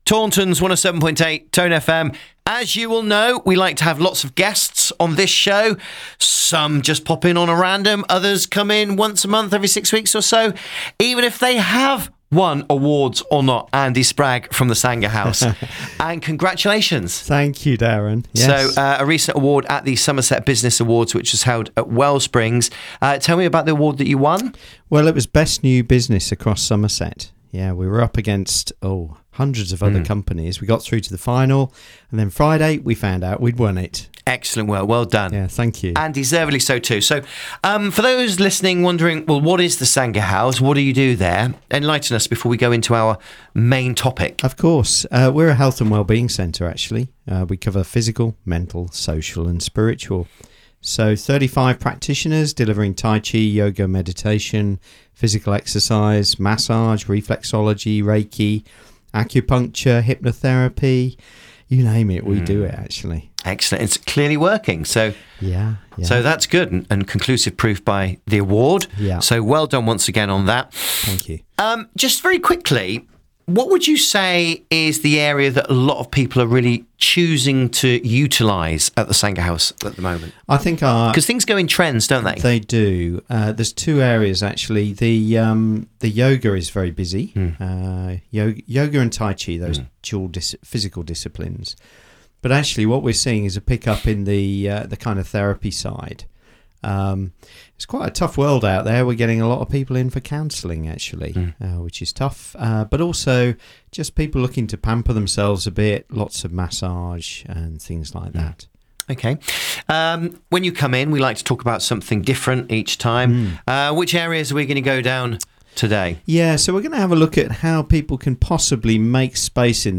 Radio Interview with Tone FM